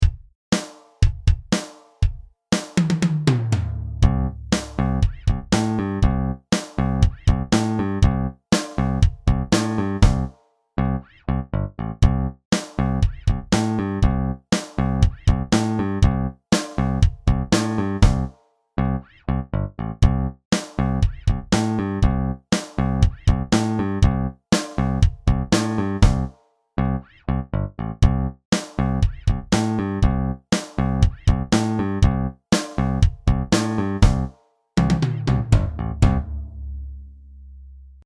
Four bar rock
• An audio backing track to play along with in class